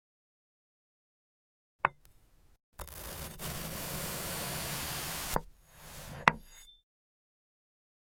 Glass Cantaloupe ASMR Sound Effects Free Download
glass cantaloupe ASMR sound effects free download